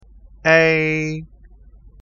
ei ออกเสียง e ที่ลิ้นอยู่หน้าก่อน แล้วค่อย ๆ ยกลิ้นให้สูงขึ้นพร้อมกับหุบปากให้แคบลง ออกเสียงต่อไปจนถึง i ซึ่งก็คือ e ➜ I = ei